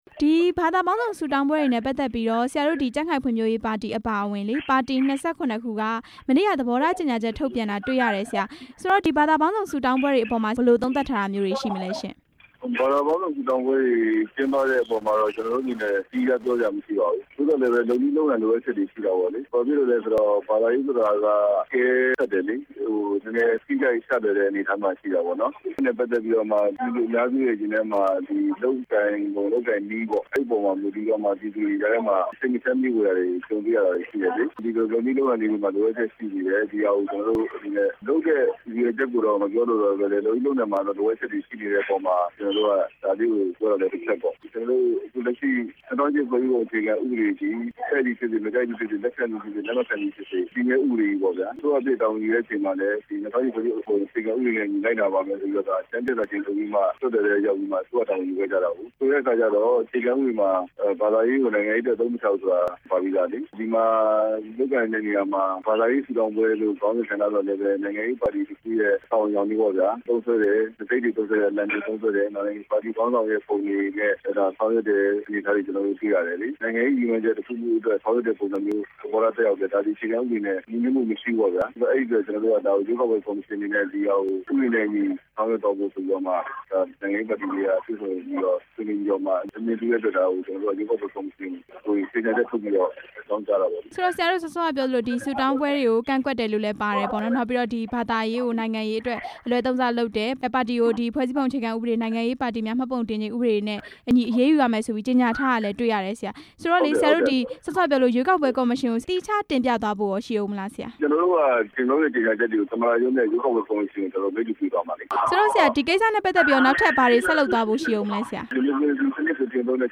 ဘာသာပေါင်းစုံ ဆုတောင်းပွဲတွေကို ကန့်ကွက် တဲ့အကြောင်း မေးမြန်းချက်